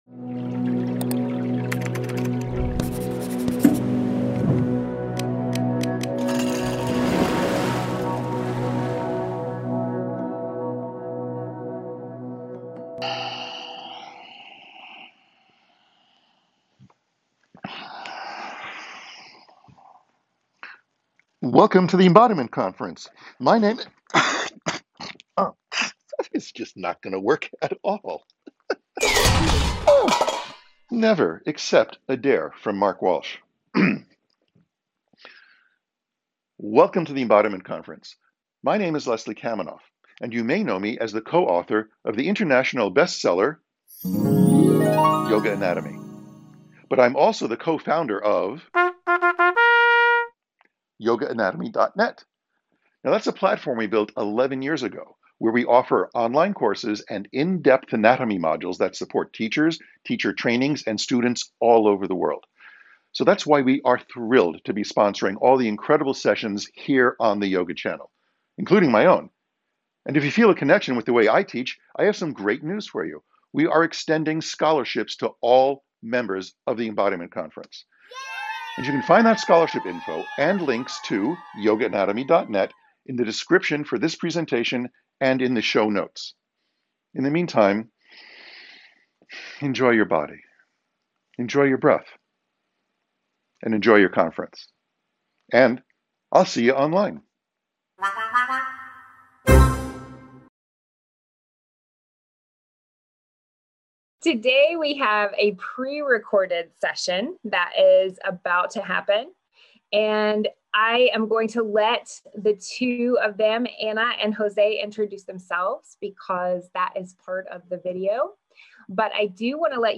Embody the Sacred Intermediate understanding Space and loose clothed required Potentially triggering In this 60 minute ceremonial yoga class, we will explore using breath, Asana & music to free up major tight areas, like neck, shoulders, hips & mind.